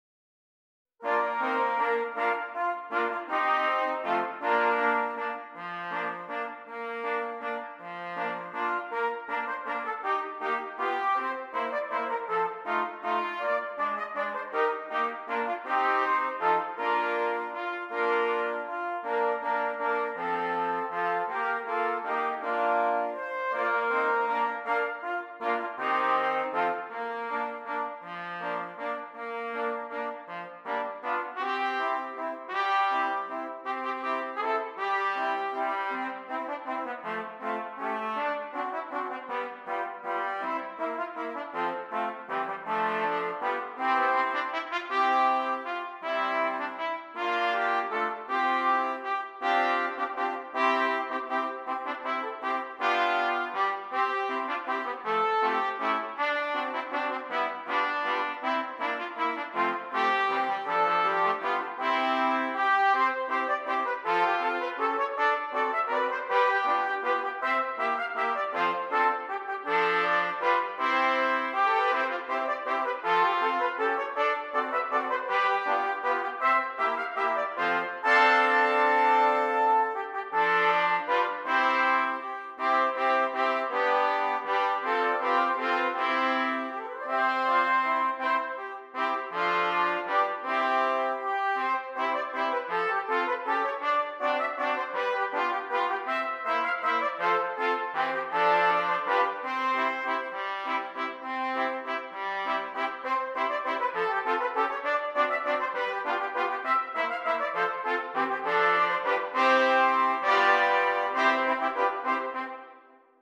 6 Trumpets
Traditional